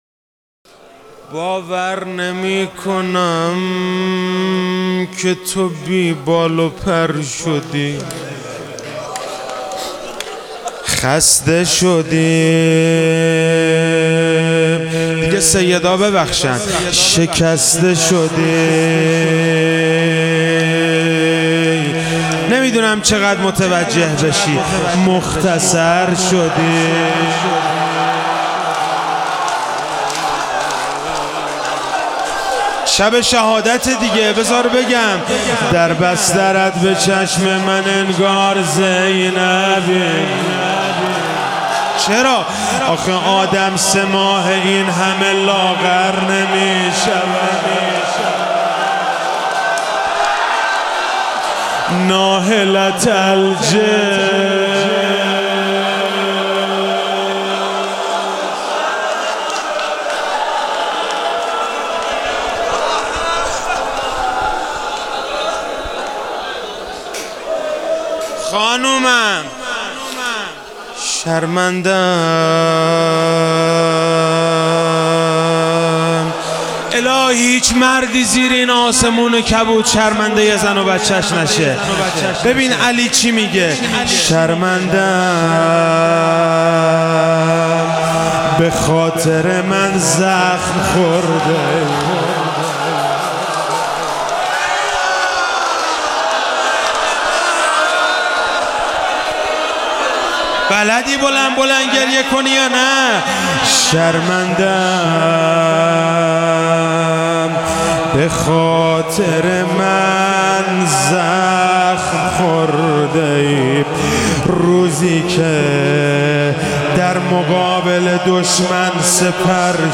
شب شهادت حضرت زهراسلام الله علیها 96- روضه - باور نمیکنم که تو